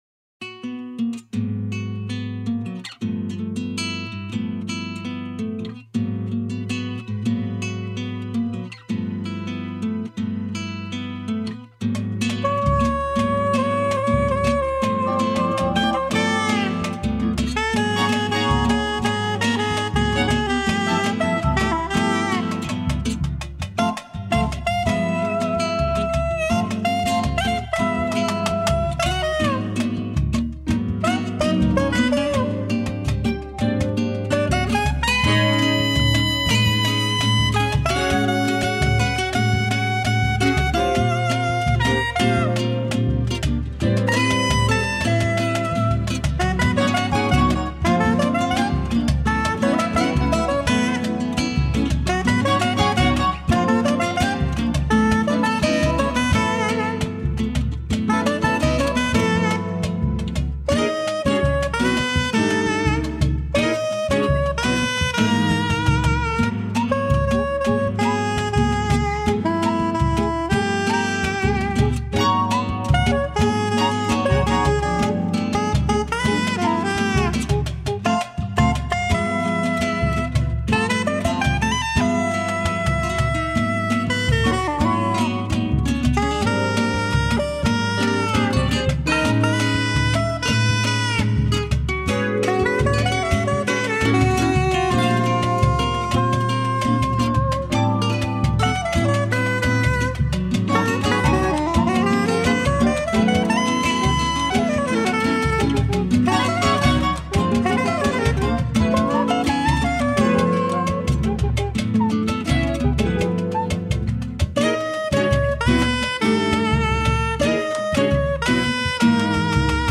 1323   04:36:00   Faixa:     Jazz